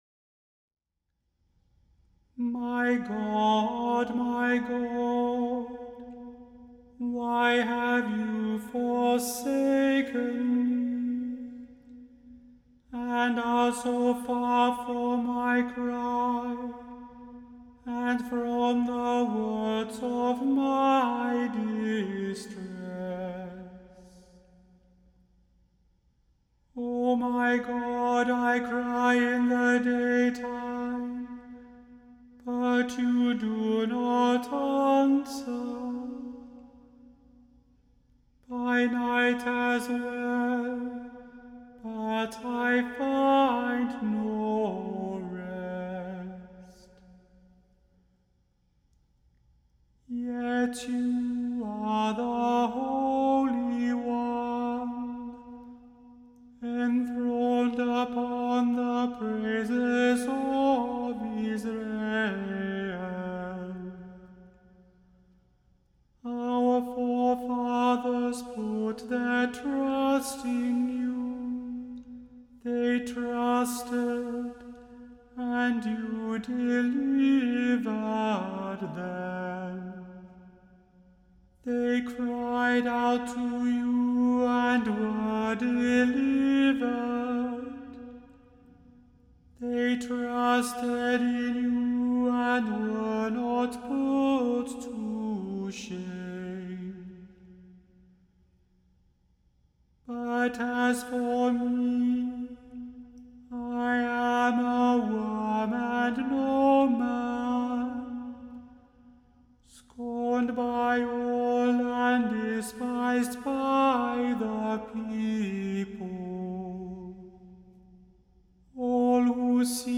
The Chant Project – Chant for Today (April 10) – Psalm 22 vs 1-18 – Immanuel Lutheran Church, New York City